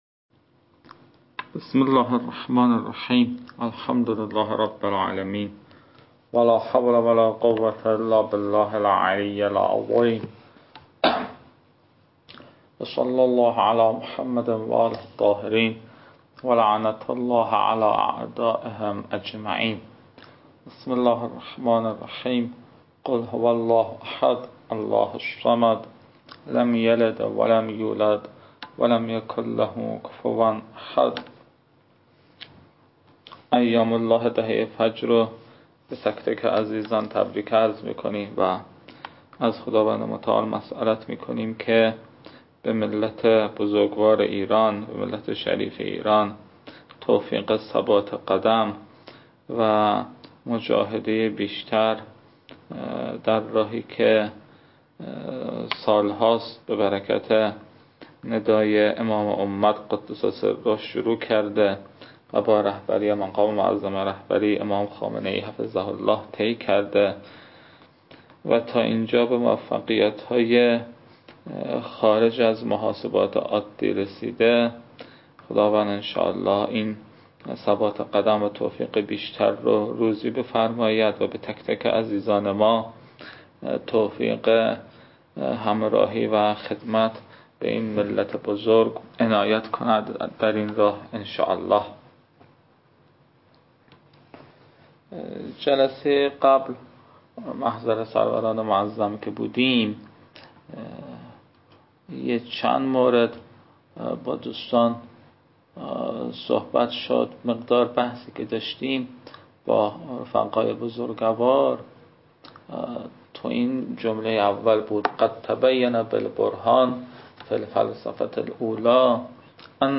تدریس رساله اول